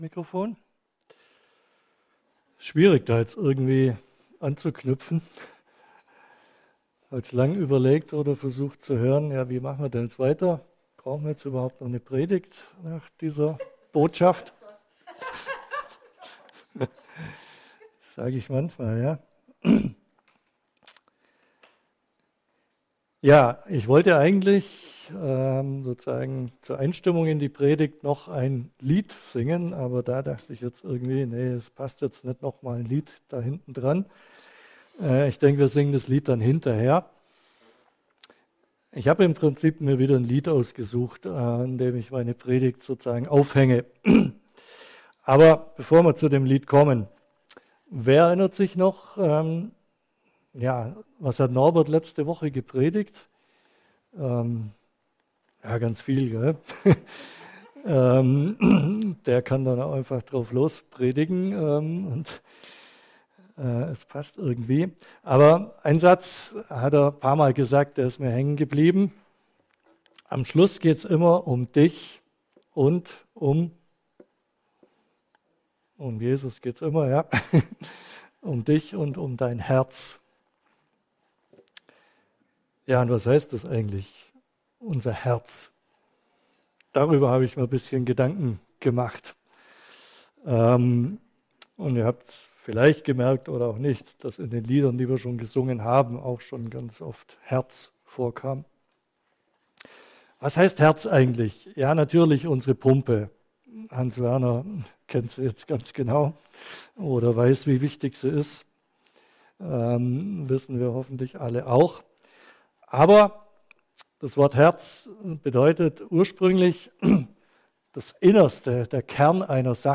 Predigt vom 01.12.2024 – Christliches Zentrum Günzburg